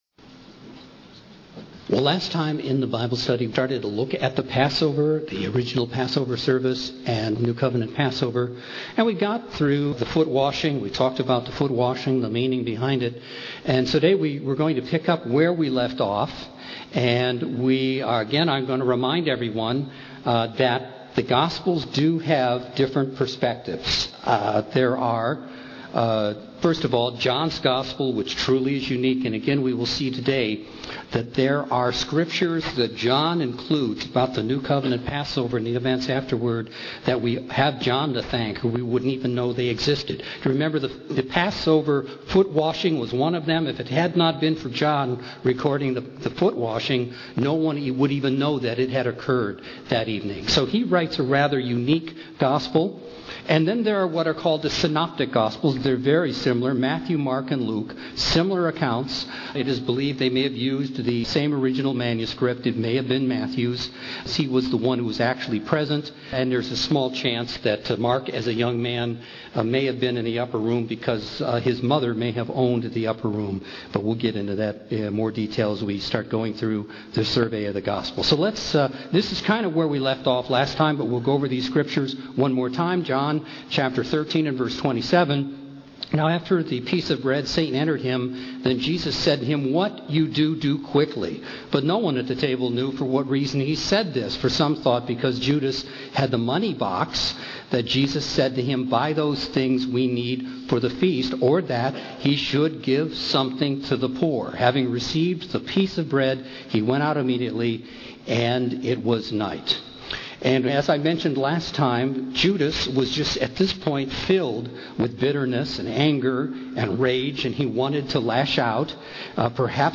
The second of a two-part Bible study series on Passover. This study continues with the study of the new covenant Passover service described in the Gospels and its meaning for us today.